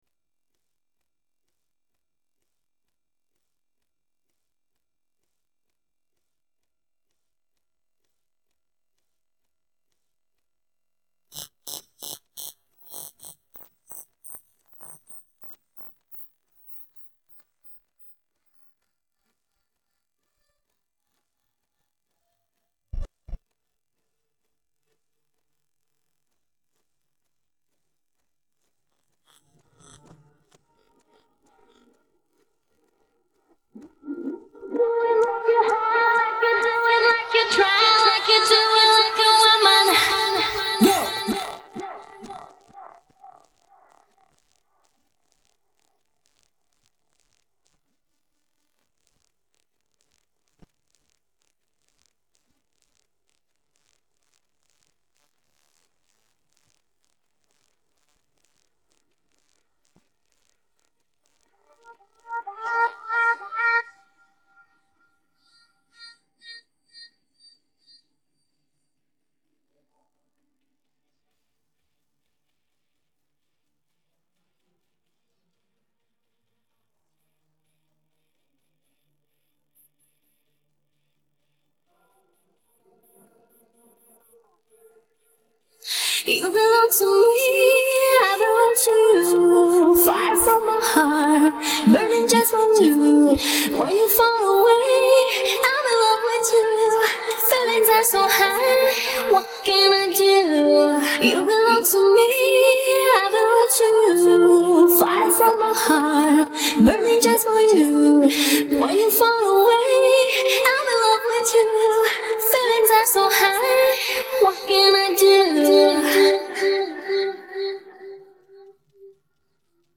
Bagian Vokal